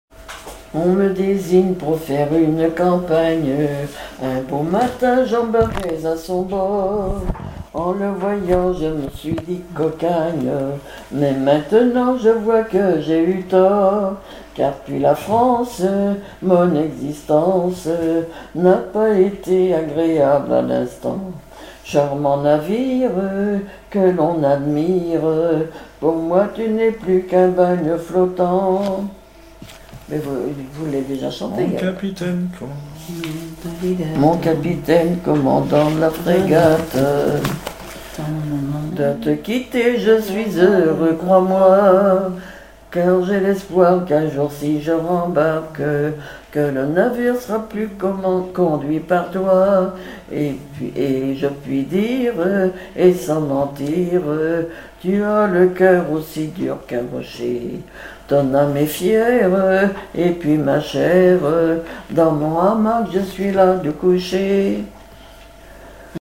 Genre strophique
Chansons maritimes
Pièce musicale inédite